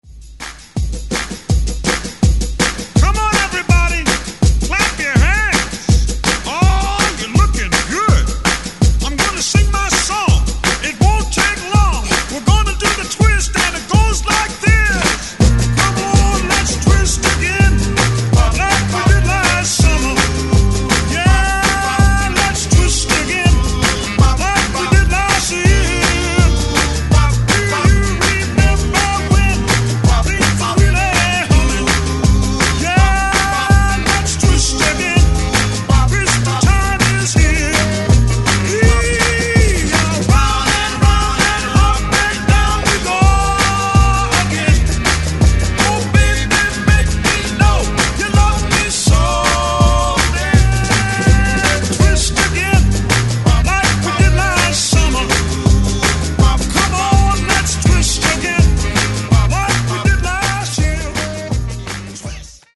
BPM: 164 Time